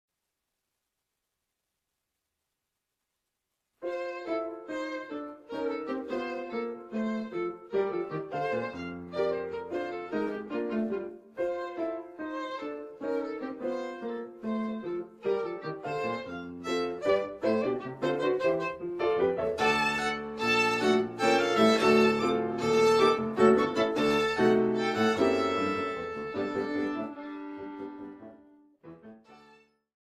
Für Violine und Klavier
Neue Musik
Duo
Violine (1), Klavier (1)